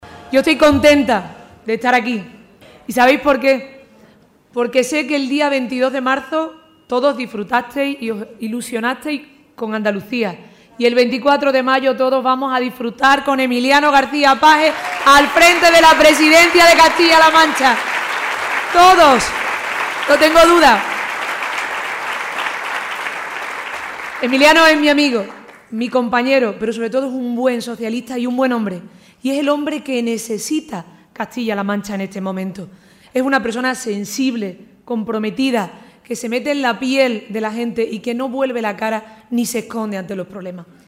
Lo ha hecho en un acto ante más de 500 personas, en el que ha estado por la Presidenta de la Junta de Andalucía, Susana Díaz.